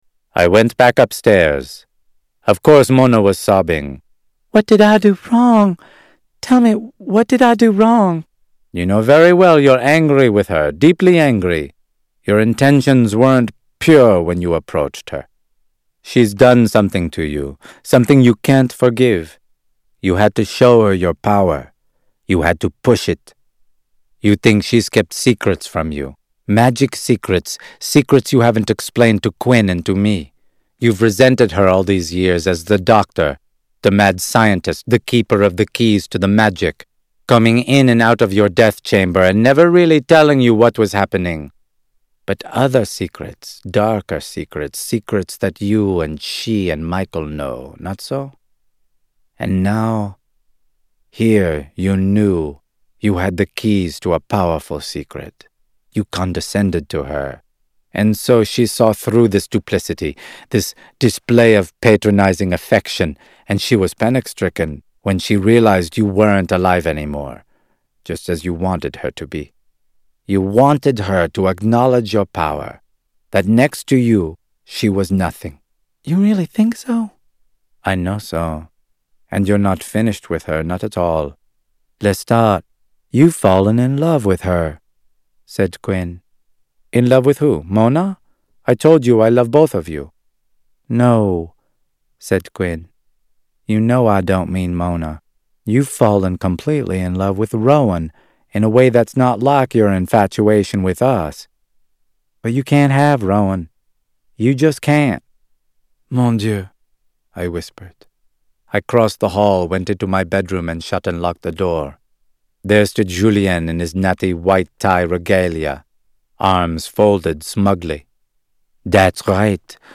Tags: Media Author Anne Rice Interview with the Vampire Audio Books